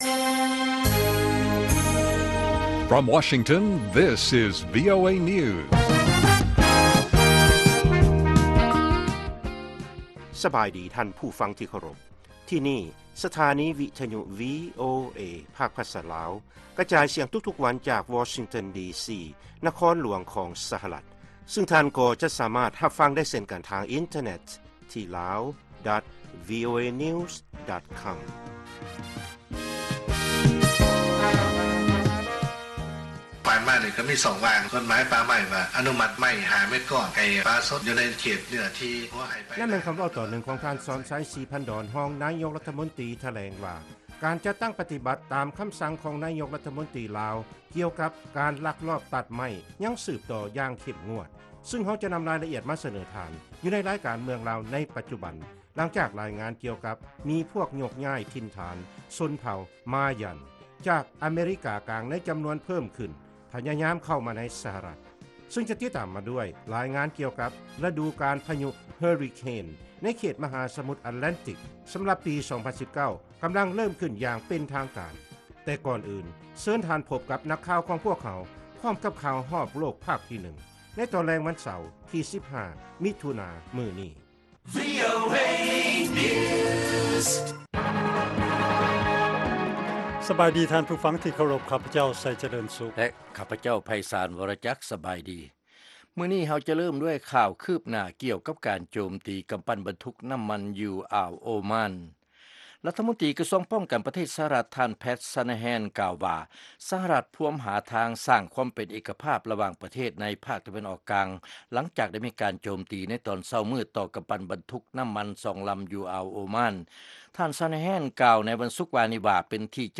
ວີໂອເອພາກພາສາລາວ ກະຈາຍສຽງທຸກໆວັນ ເປັນເວລາ 30 ນາທີ. ພວກເຮົາສະເໜີຂ່າວ ຂໍ້ມູນ ແລະລາຍງານທີ່ໜ້າສົນໃຈ ກ່ຽວກັບເຫດການທີ່ເກີດຂຶ້ນໃນປະເທດລາວ ສະຫະລັດອາເມຣິກາ ເອເຊຍແລະຂົງເຂດອື່ນໆຂອງໂລກ ຕະຫລອດທັງບົດຮຽນພາສາອັງກິດ ແລະລາຍການເພງຕາມຄຳຂໍຂອງທ່ານຜູ້ຟັງ.